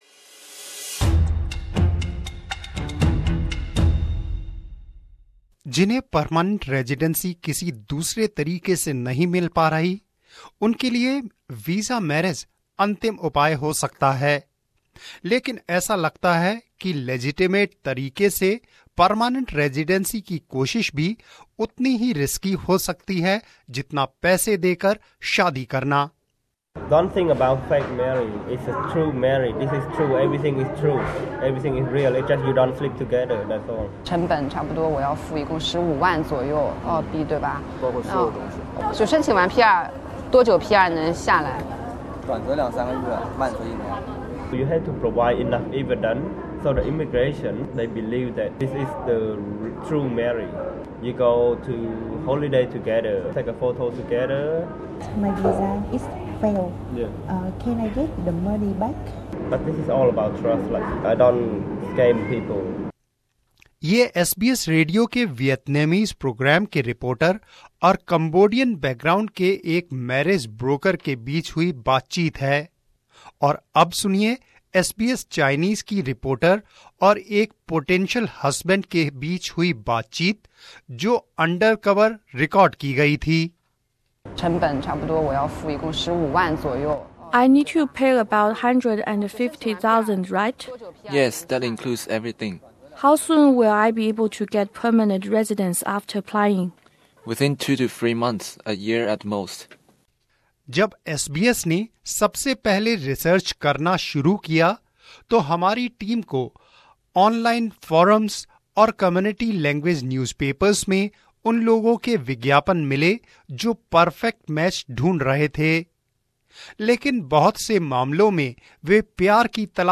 In a special investigation, SBS Viceland's The Feed explores the underground market where Australian permanent residency is being bought and sold. They find dodgy agents and job brokers, and hear from victims who've lost thousands of dollars in their pursuit of the Australian dream.
This is a real conversation between an SBS reporter from the Vietnamese program and a marriage broker of Cambodian background. The following is also an undercover recording, of a conversation between an SBS Chinese reporter and a potential husband.